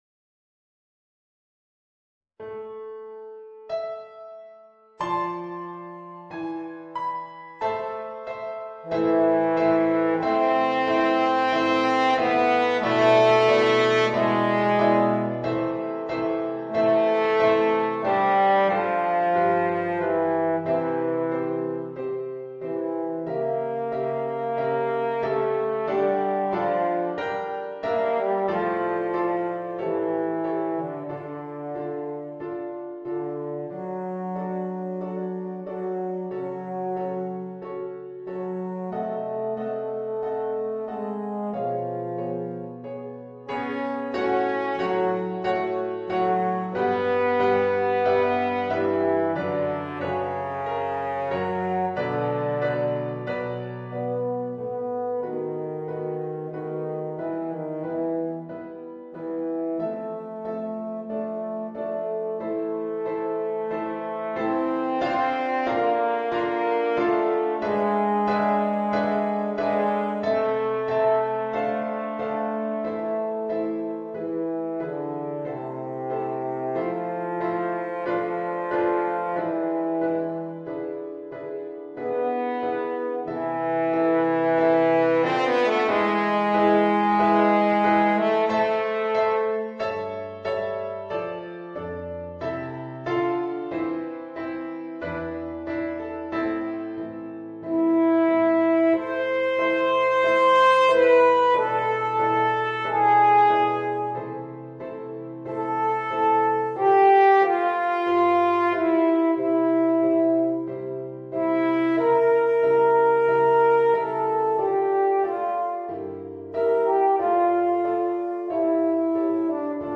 Voicing: Horn and Organ